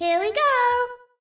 toadette_here_we_go.ogg